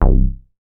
MoogRous 002.WAV